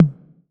6TOM HI 1.wav